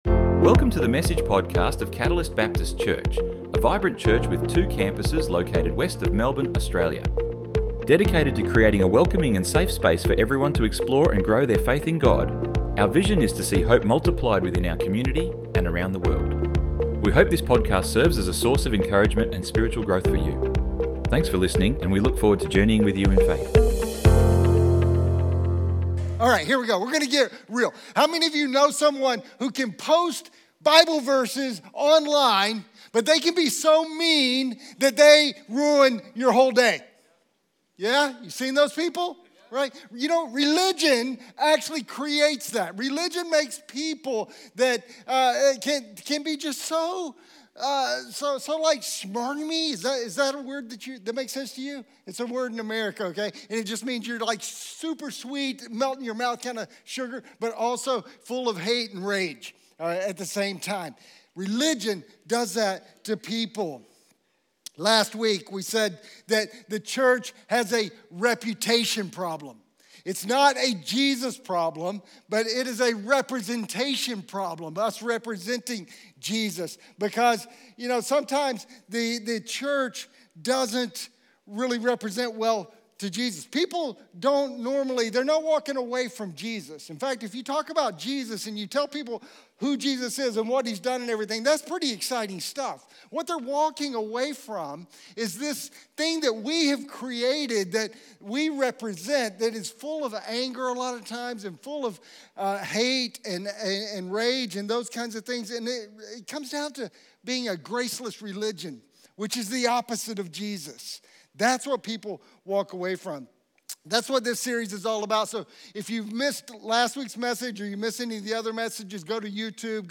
Download Download Sermon Notes 02-real-or-religious-Jesus-is-the-only-way.pdf 02 -online notes - Real or Religious - One Way - Jesus.doc Which way are you walking?